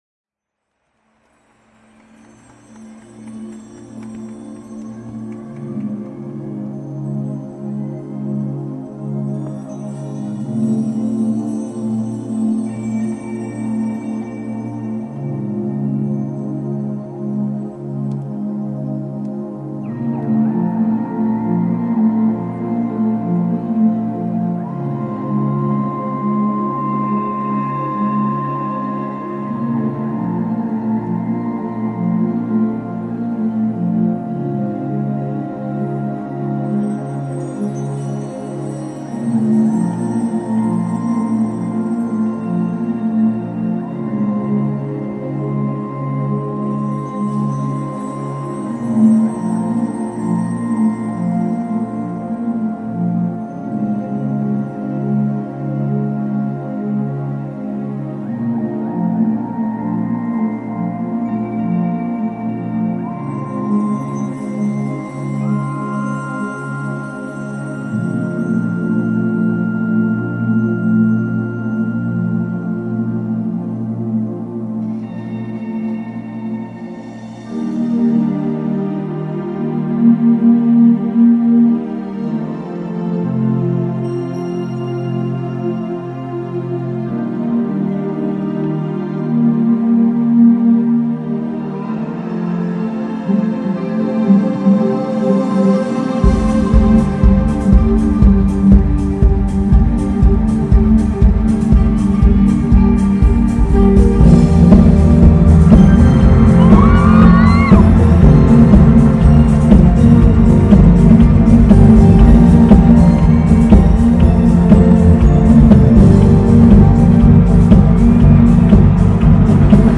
演唱会版